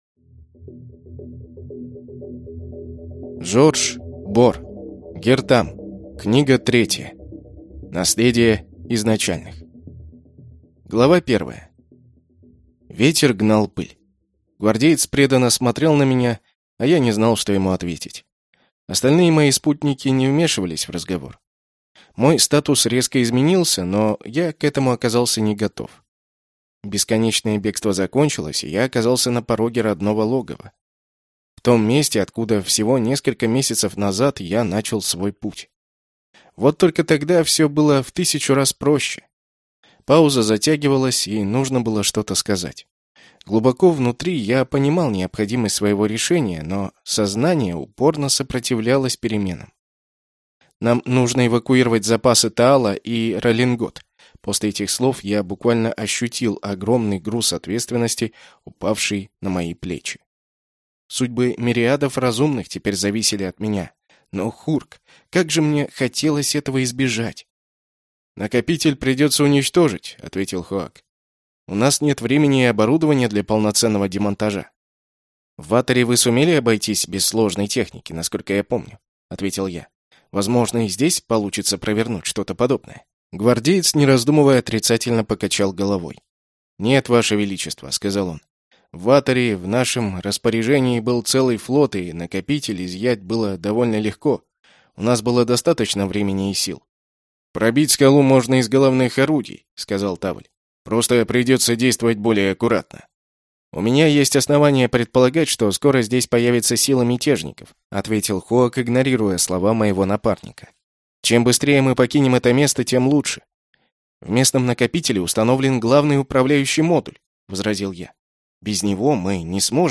Аудиокнига Гиртам. Наследие Изначальных | Библиотека аудиокниг